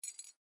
钥匙扣 " 钥匙扣12
描述：录音设备：Sony PCMM10Format：24 bit / 44.1 KHz
Tag: 样品 录音 弗利